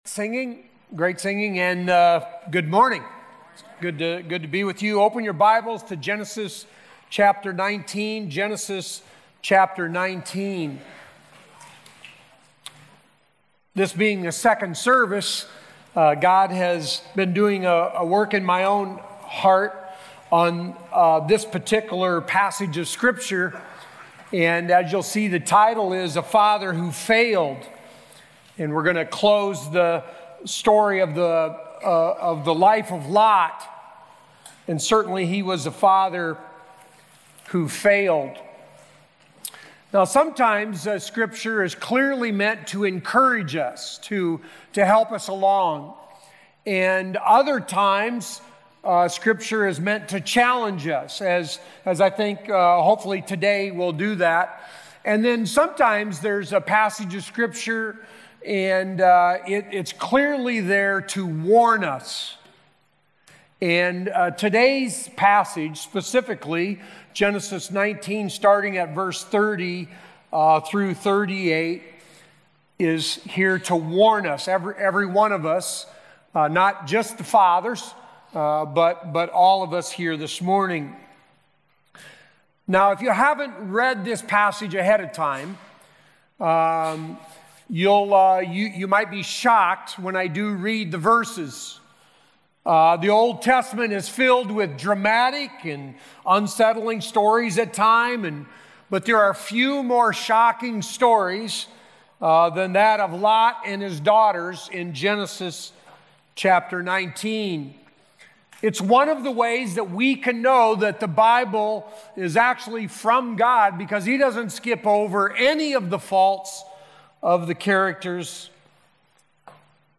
Sermons The World According to God